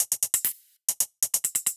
Index of /musicradar/ultimate-hihat-samples/135bpm
UHH_ElectroHatB_135-02.wav